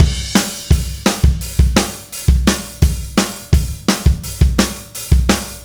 Indie Pop Beat 03 Crash.wav